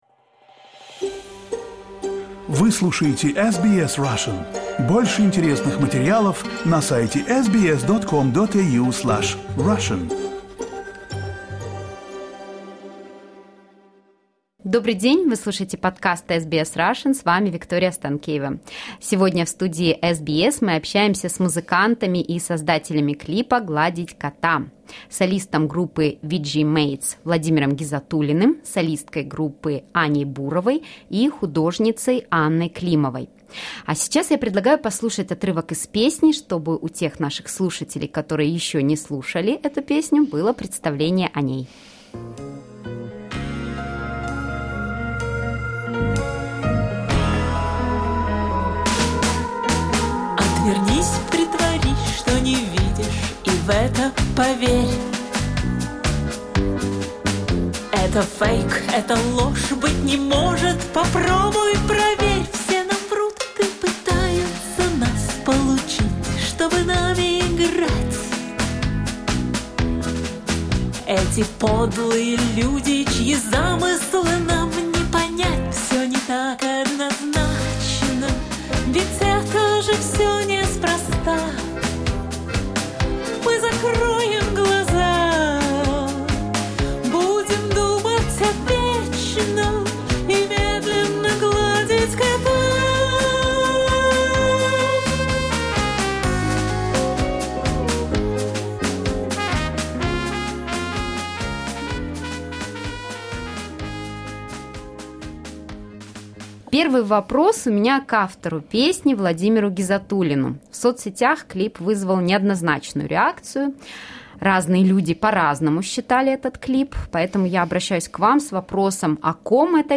Интервью с создателями клипа «Гладить кота
в студии SBS